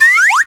jump.ogg